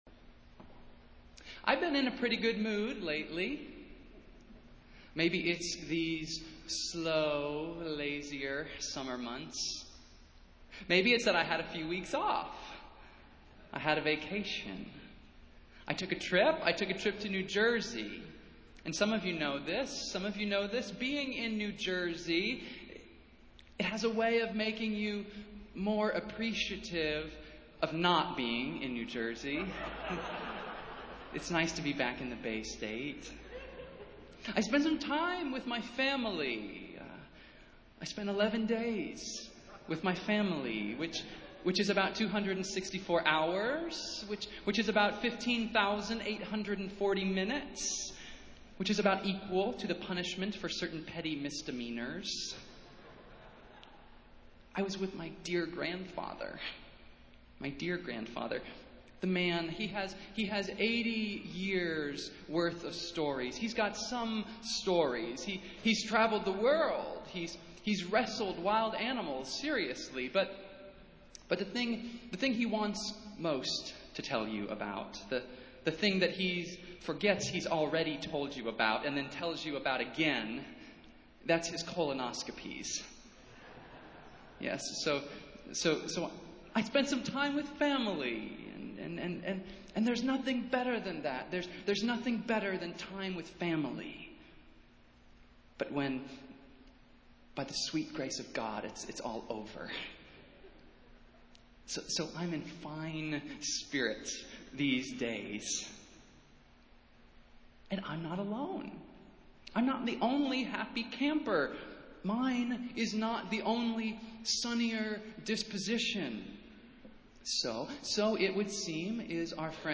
Festival Worship - Sixth Sunday after Pentecost